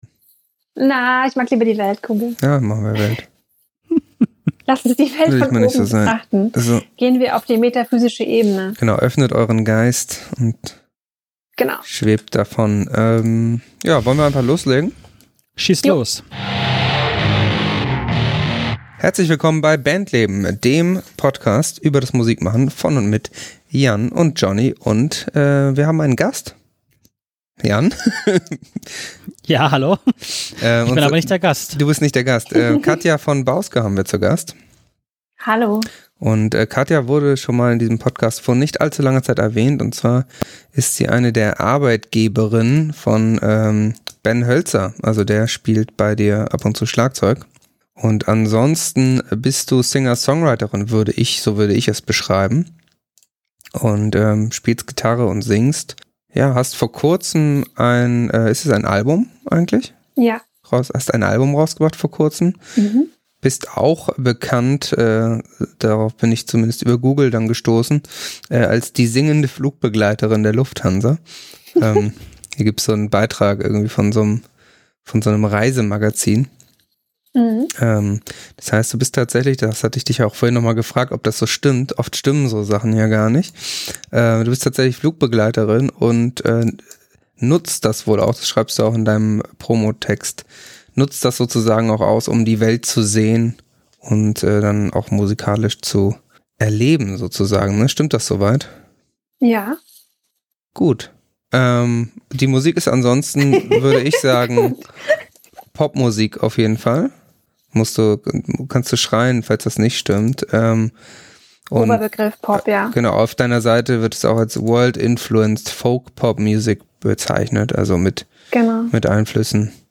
Die singende Flugbegleiterin hat sich per Fernschalte mit den Bandleben Boys über ihren Werdegang und ihr aktuelles Album unterhalten.